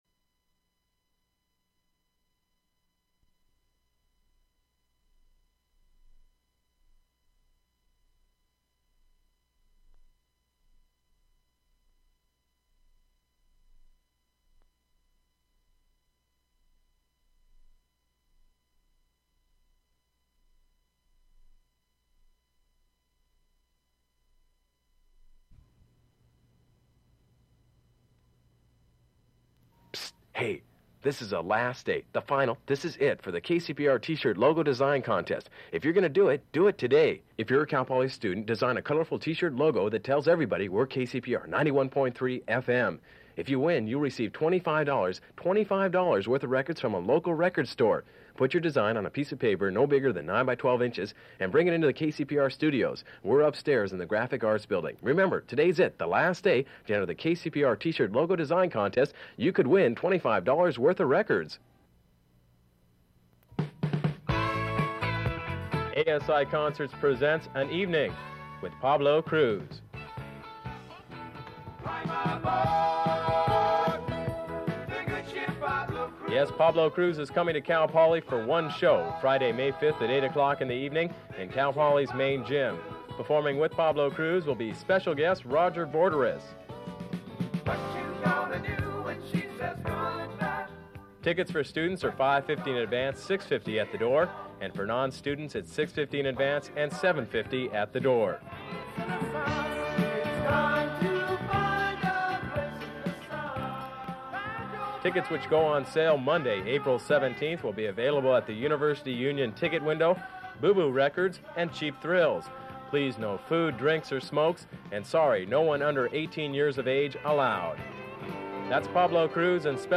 Spots Archives, April 1978 to November 1978